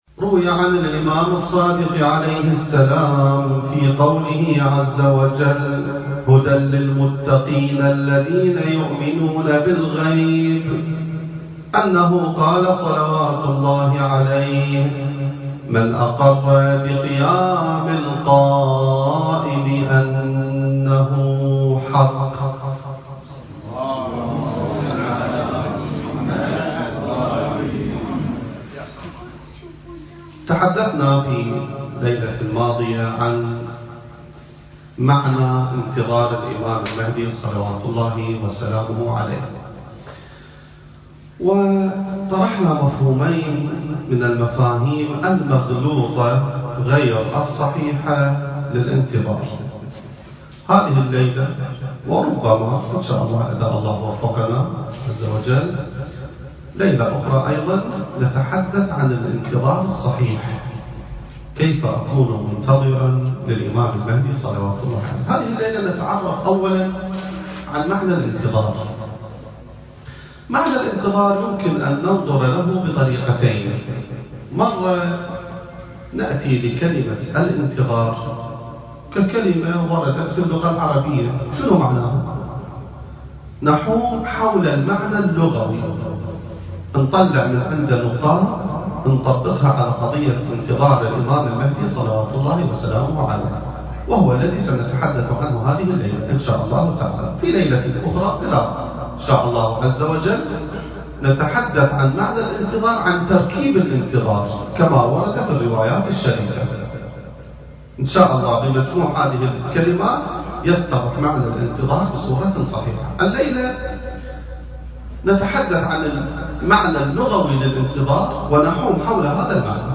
المكان: مؤسسة الإمام السجاد (عليه السلام) / استراليا التاريخ: 2019